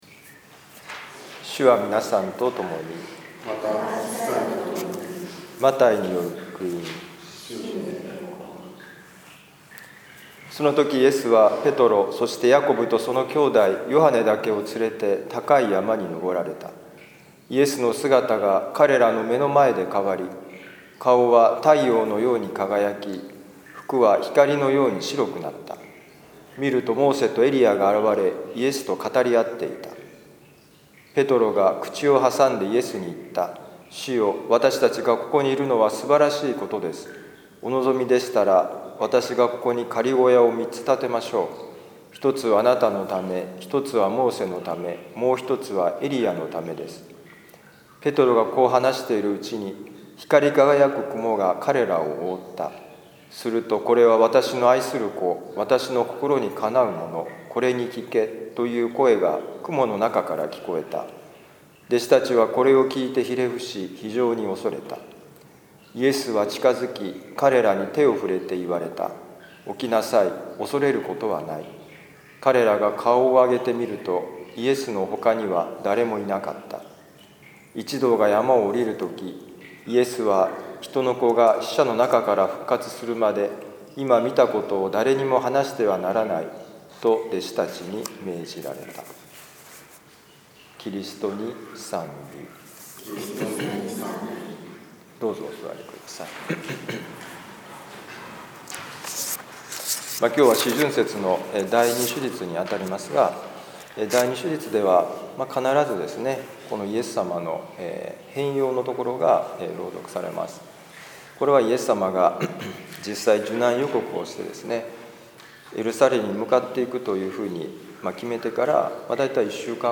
マタイ福音書17章1-9節「困難の中でこそ神の声を聴こう」2020年3月8日ザビエル聖堂での小さなミサ
この福音書朗読とお説教ではこんなお話が聞けます